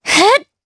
Laudia-Vox_Attack2_jp_b.wav